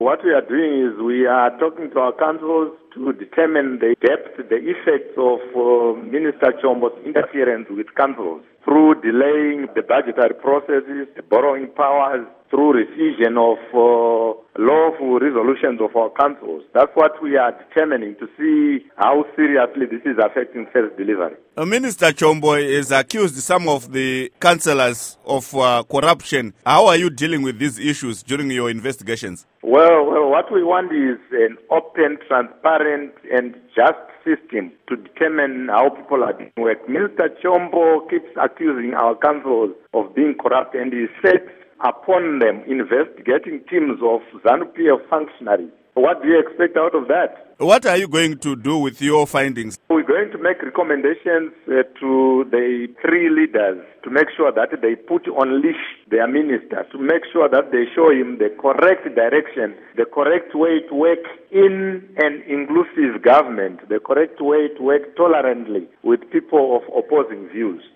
Interview With Sesil Zvidzai